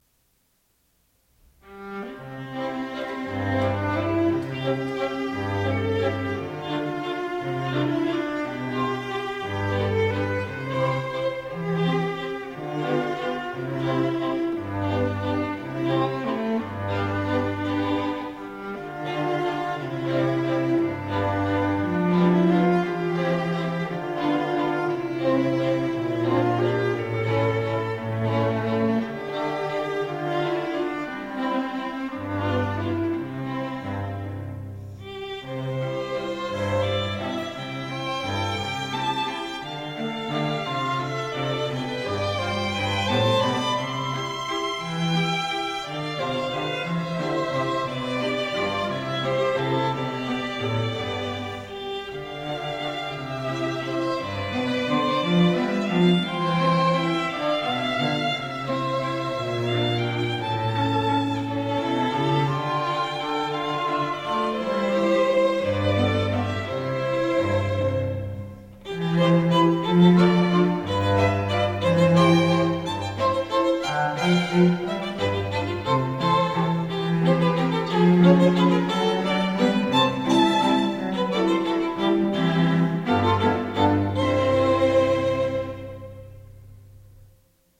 ELEGANT STRING MUSIC FOR ANY OCCASION